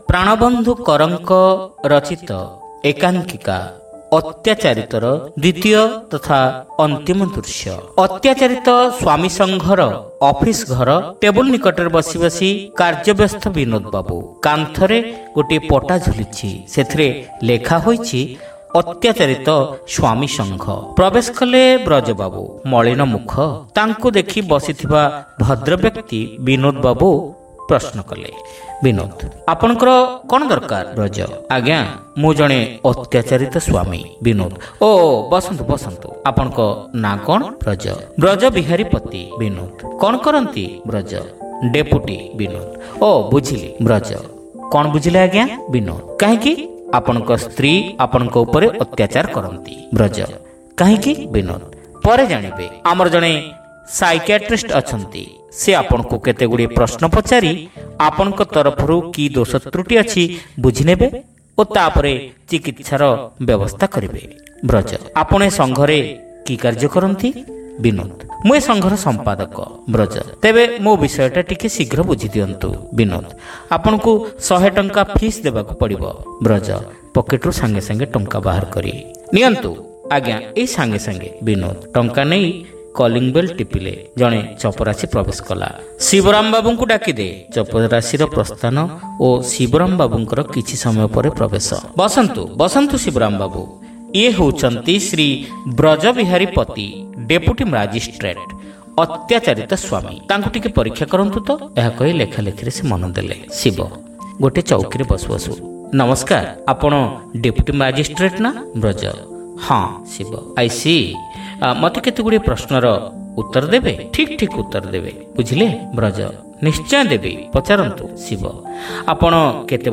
Audio One act Play : Atyacharita (Part-2)